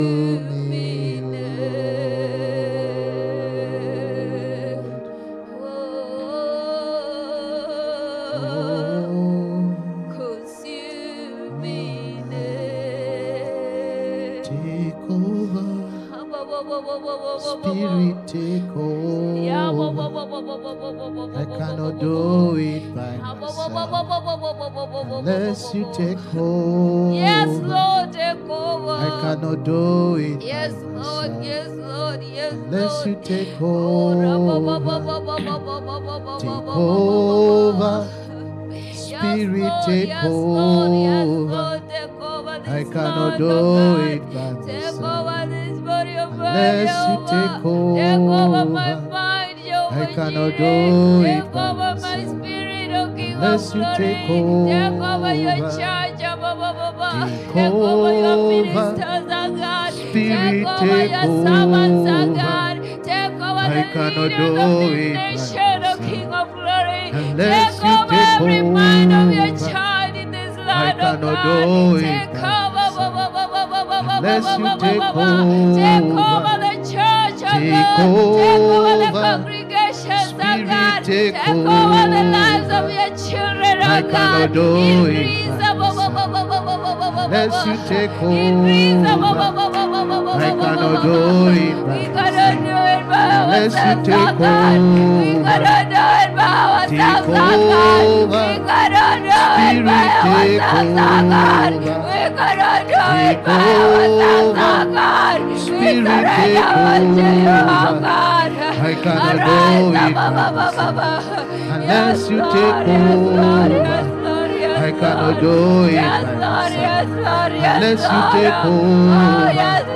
Sunday Intercession- Tongues of Fire 🔥